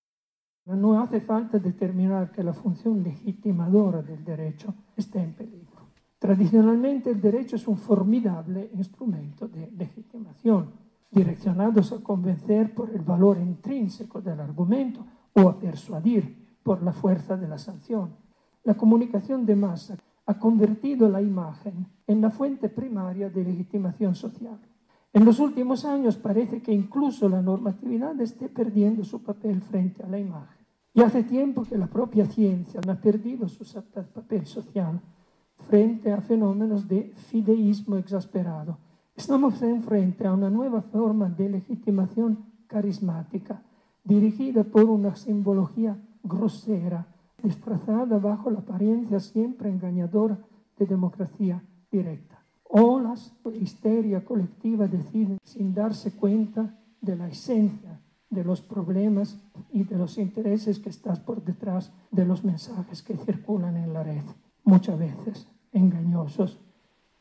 “En los últimos años, pareciera que la normatividad está perdiendo su papel frente a la imagen, y hace tiempo que la propia ciencia ha perdido su función social ante fenómenos de fideísmo exasperado, religioso o laico”, dijo en la conferencia magistral “Algunas funciones del derecho y su problemático cumplimiento en el mundo actual”.